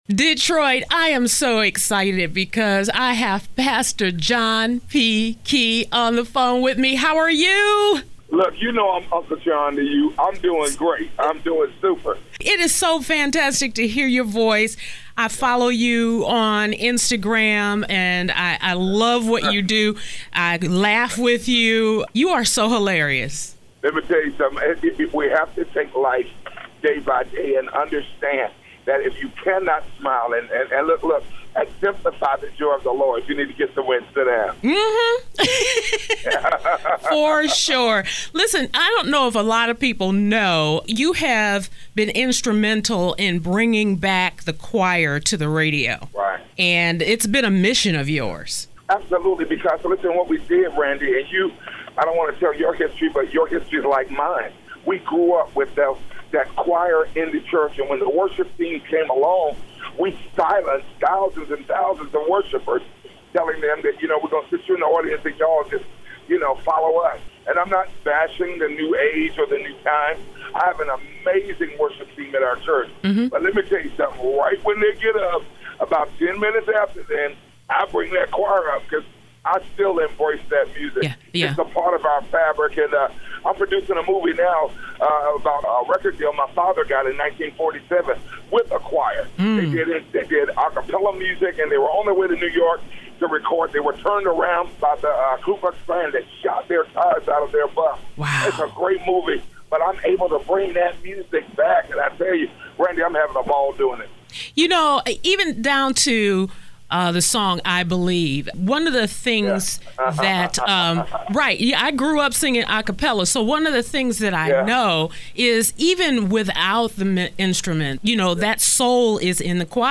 Today, I talked to him about his new song, “I Made It Out,” the resurgence of choir music, and why the choir is so important.